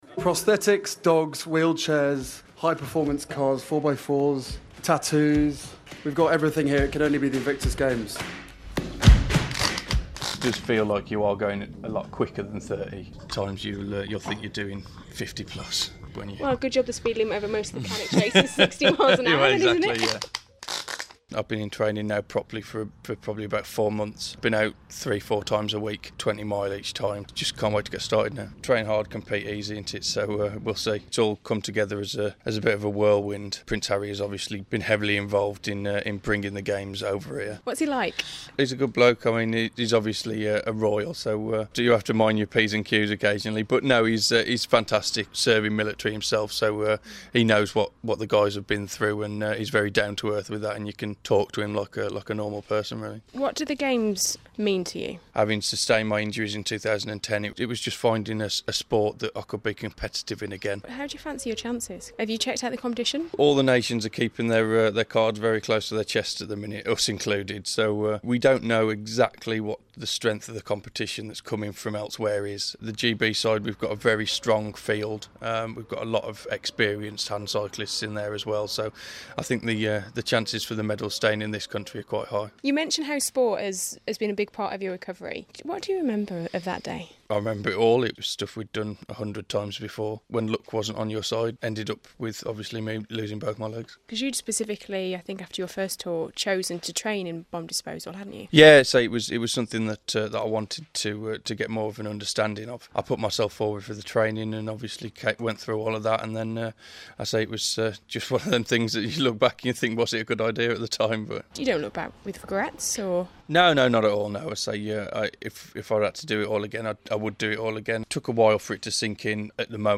(Broadcast on BBC Radio Stoke)